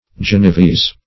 Search Result for " genevese" : The Collaborative International Dictionary of English v.0.48: Genevese \Gen`e*vese"\, a. [Cf. L. Genevensis, F. g['e]nevois.] Of or pertaining to Geneva, in Switzerland; Genevan.